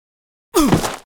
SFX男呻吟倒地8音效下载
SFX音效